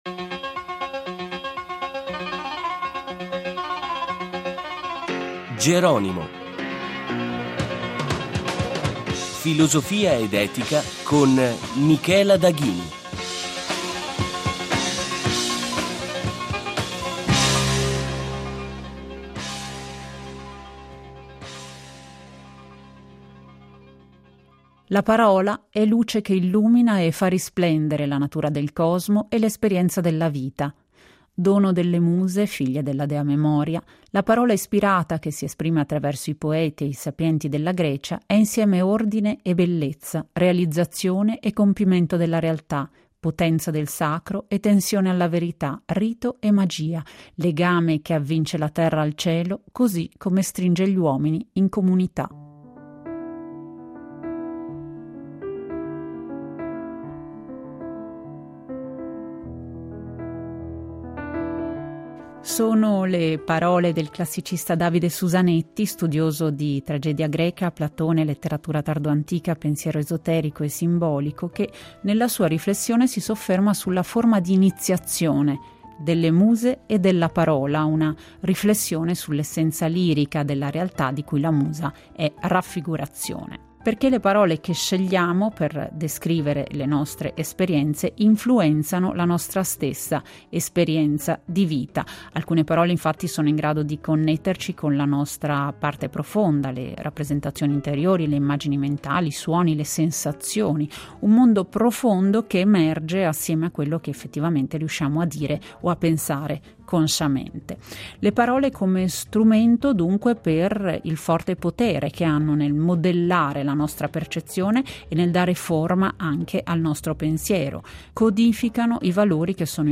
Abbiamo incontrato lo studioso dopo la conferenza per parlarne.